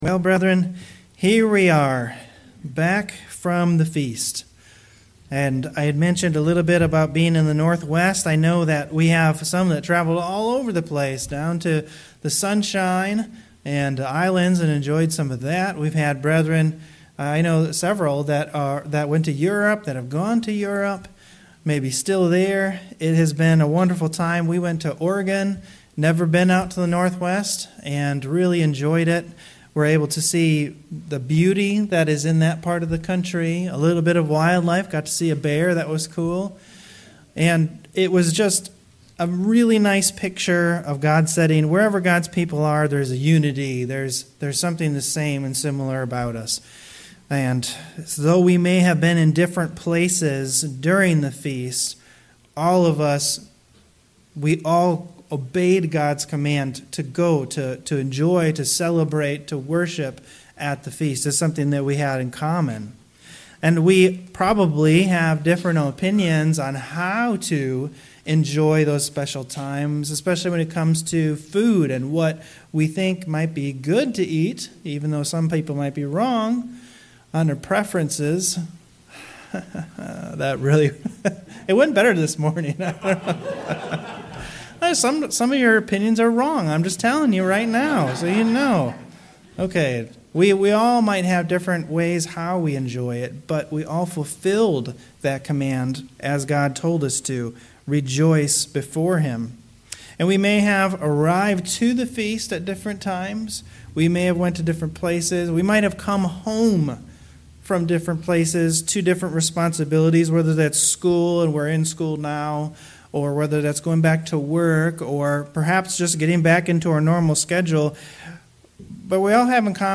Sermons
Given in Cleveland, OH North Canton, OH